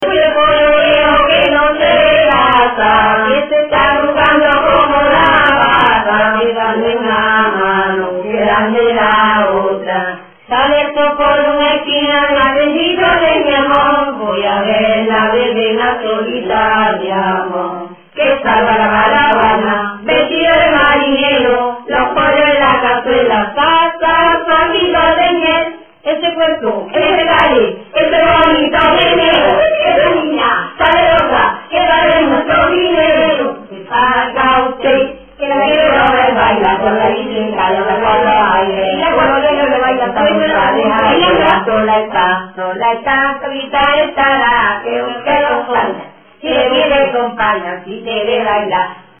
Materia / geográfico / evento: Canciones de corro Icono con lupa
Arenas del Rey (Granada) Icono con lupa
Secciones - Biblioteca de Voces - Cultura oral